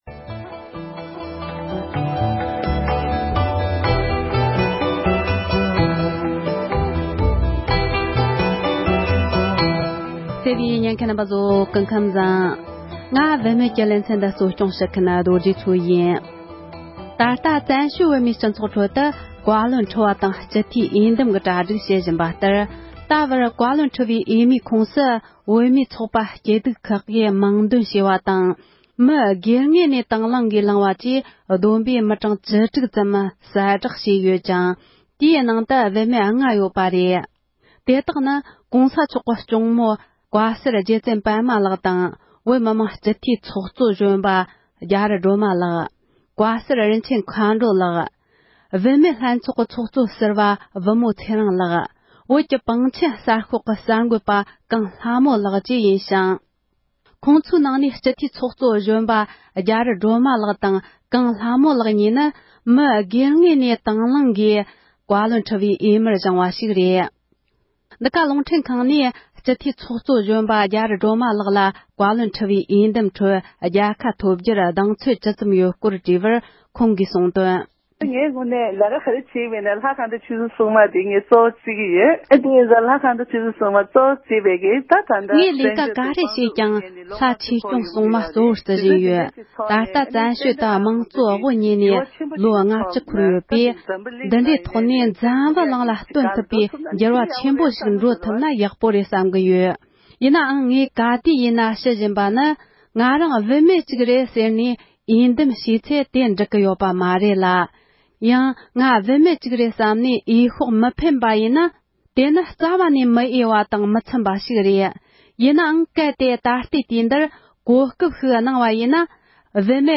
བཀའ་བློན་ཁྲི་པའི་འོས་མི་ནང་བུད་མེད་ལྔ་ཙམ་གྱི་མིང་བཏོན་ཡོད་པ་རྣམས་ལ་དགོངས་ཚུལ་བཀའ་འདྲི་ཞུས་པ
སྒྲ་ལྡན་གསར་འགྱུར།